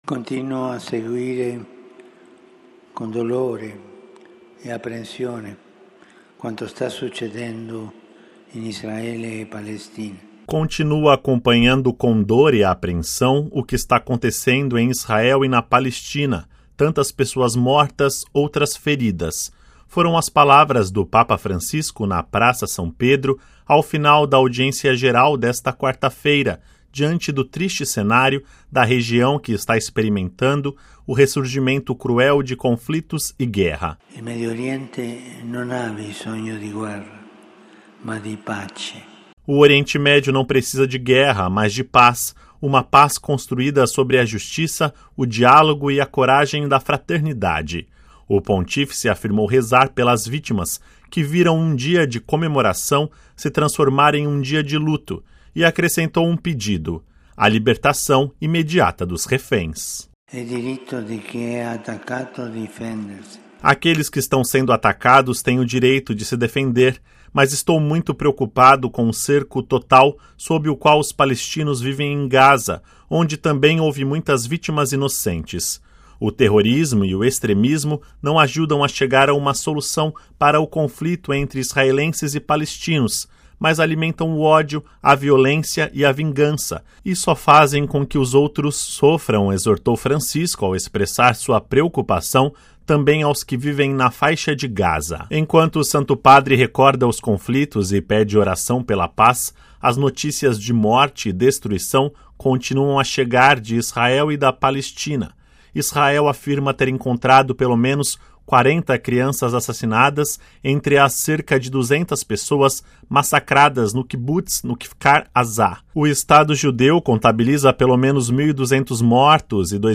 Ouça com a voz do Papa e compartilhe:
“Continuo acompanhando com dor e apreensão o que está acontecendo em Israel e na Palestina: tantas pessoas mortas, outras feridas”, foram as palavra do Papa Francisco, na Praça São Pedro, ao final da audiência geral desta quarta-feira, 11 de outubro, diante do triste cenário da região que está experimentando o ressurgimento cruel de conflitos e guerra.